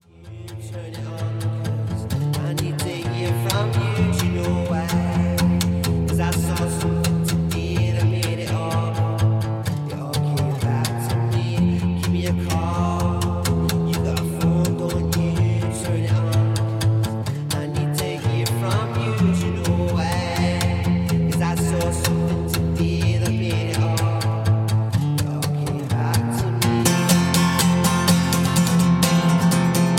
Backing track files: 2000s (3150)